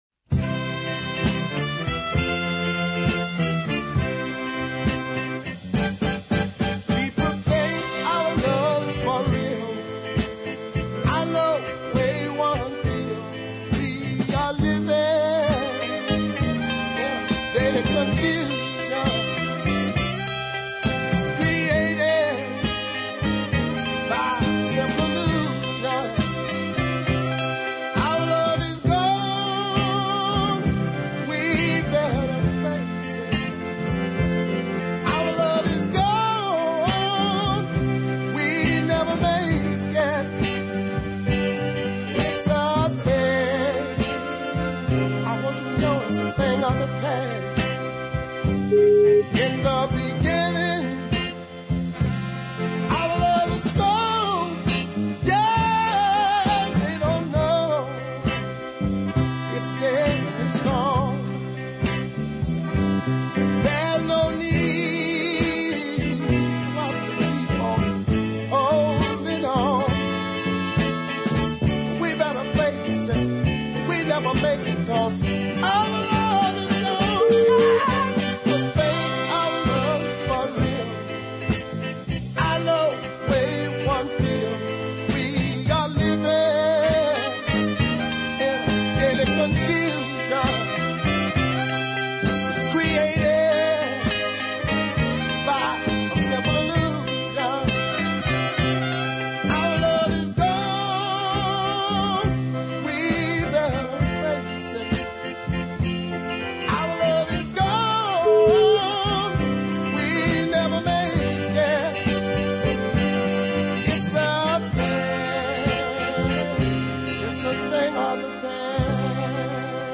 cut in Memphis